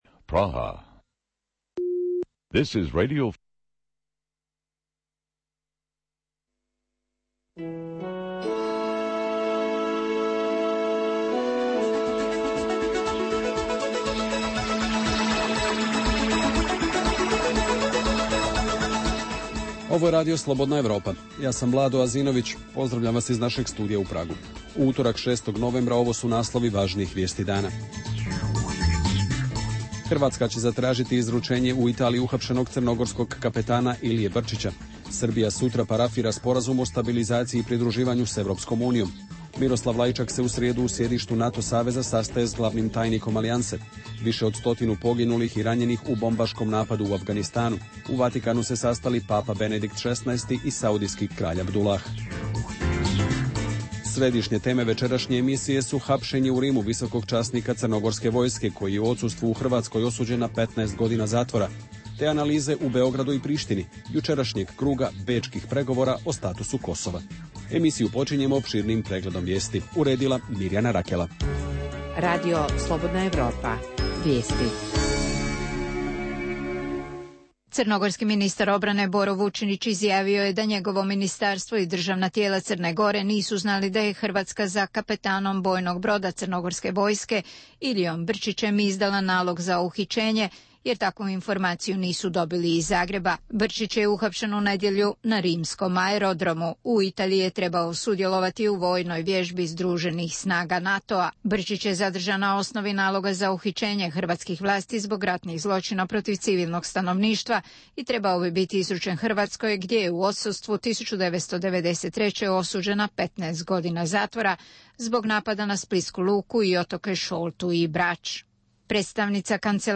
Emisija o dešavanjima u regionu (BiH, Srbija, Kosovo, Crna Gora, Hrvatska) i svijetu. Prvih pola sata emisije sadrži regionalne i vijesti iz svijeta, te najaktuelnije i najzanimljivije teme o dešavanjima u zemljama regiona i teme iz svijeta. Preostalih pola sata emisije, nazvanih “Dokumenti dana” sadrži analitičke teme, intervjue i priče iz života.